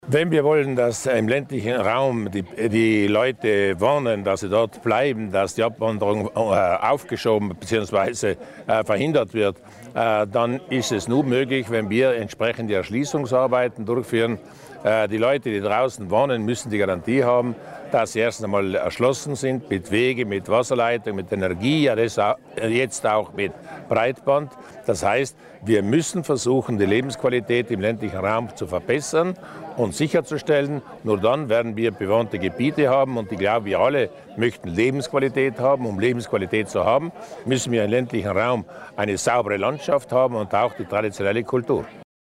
Landeshauptmann Durnwalder zur Bedeutung des Erschließungswegs zu den Muthöfen